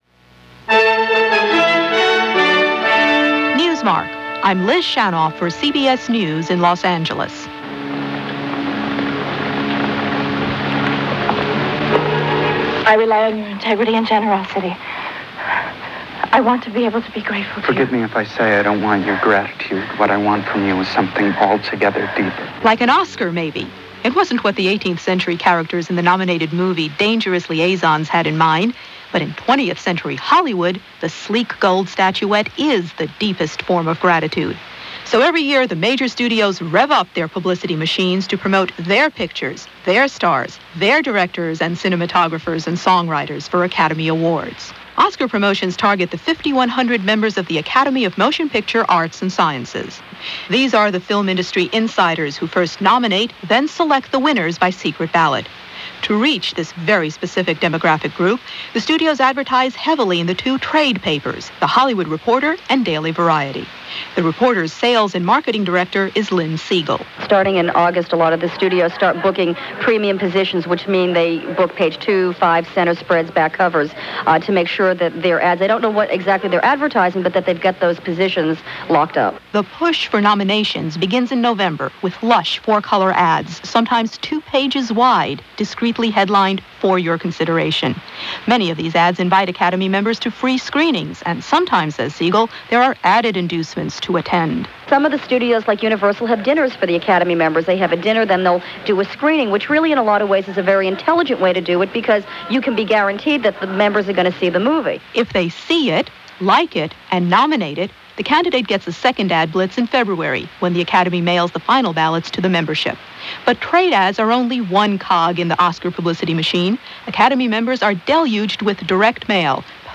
In recent years questions arise about the ethics of the Oscar ceremony, about the nominating process – how much money the studios pump in to make sure their chosen film wins and wins big. Been that way forever, as this segment of the CBS Radio program Newsmark makes clear – even in 1989 a fortune was spent getting the message out; persuading minds and winning votes.